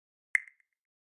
Уведомления, оповещения, сообщения гугл почты Gmail в mp3